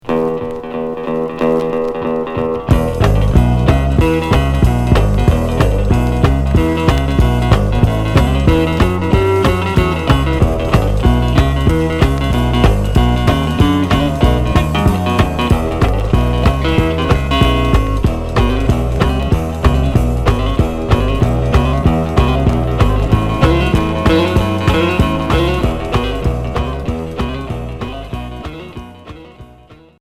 Rock boogie